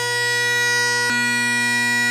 and finally the low As from the beginning of each spliced together:
All Kinnaird then Canning tenors swapped in, low A only
The mic is about chanter level but behind and to my left, tenor side.
kinnaird_canning-tenors-low_A.wav